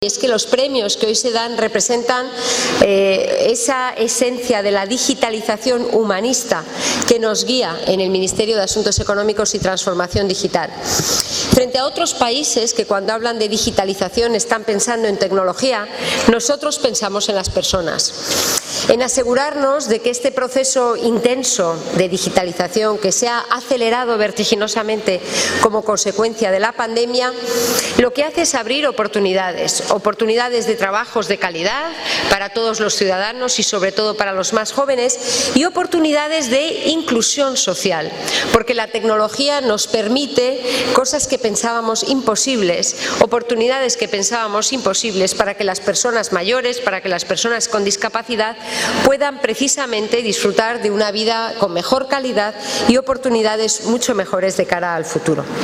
Calviño afirmó formato MP3 audio(1,06 MB) que “los Premios Discapnet representan la esencia de la “digitalización humanista” del ministerio que ella dirige, porque, como el gobierno, ponen en el centro de su quehacer siempre a las personas.